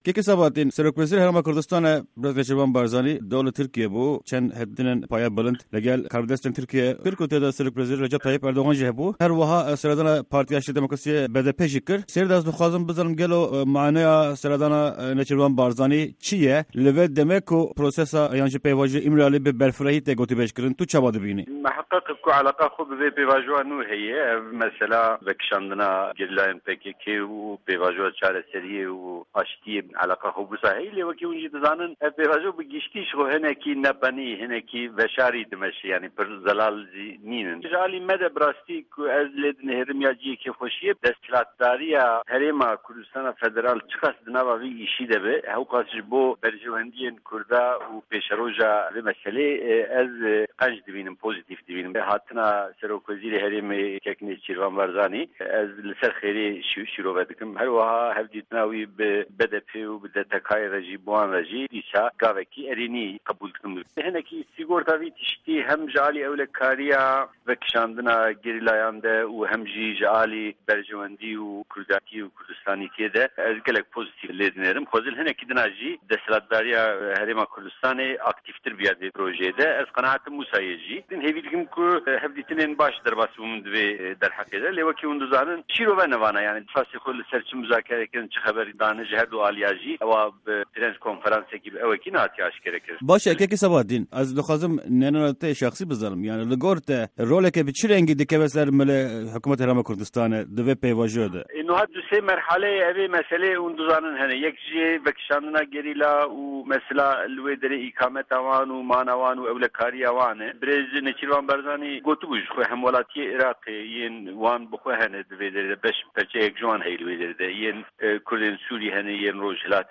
Di hevpeyvîna Pişka Kurdî ya Dengê Amerîka de sîyasetvan û hiqûqnas